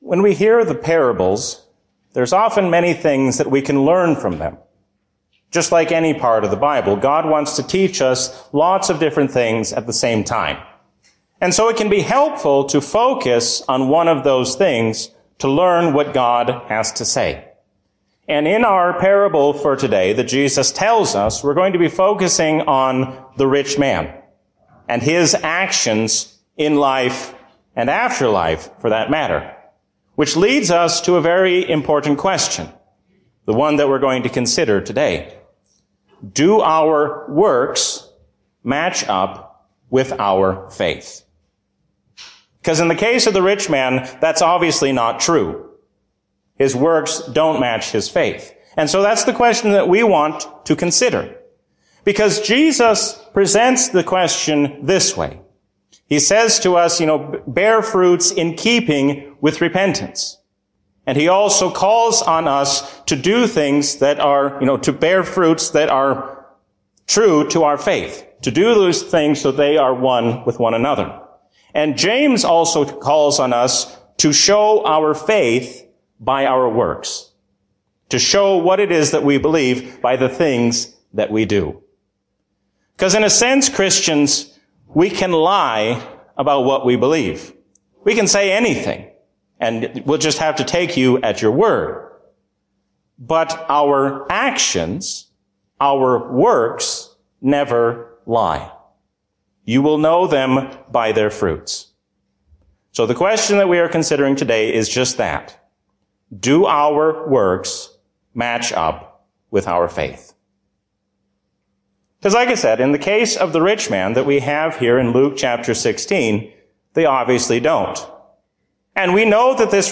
A sermon from the season "Trinity 2023." Listen to Jesus and do what He says, because He is your loving Lord.